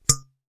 Metalic 1.wav